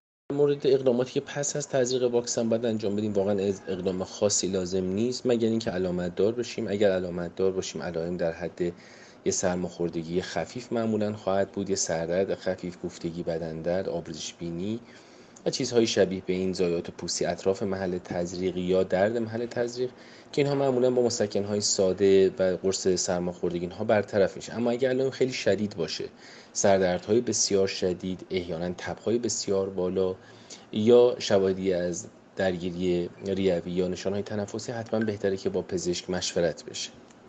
در گفتگو با جام جم آنلاين